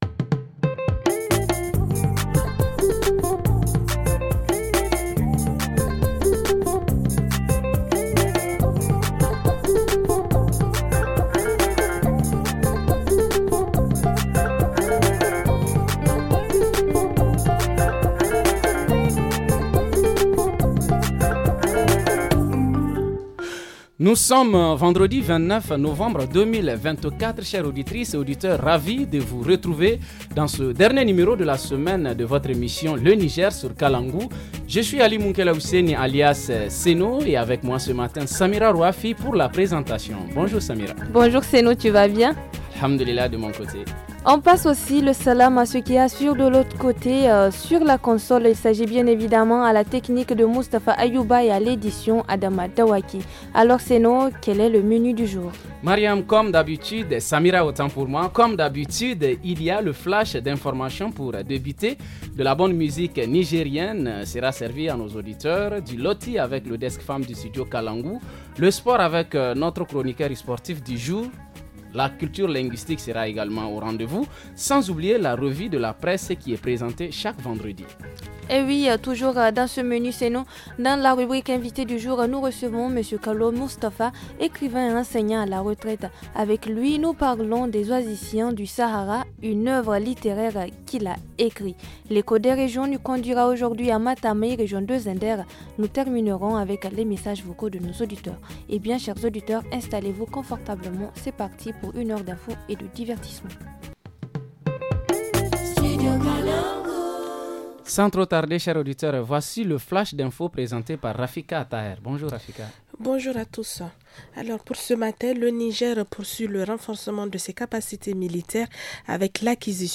Dans la rubrique hebdomadaire, nous parlerons du groupement des femmes en situation de handicap à Dosso. En reportage région, point sur le ‘Thcin talia’, culture locale devenue une innovation culturelle à Matameye.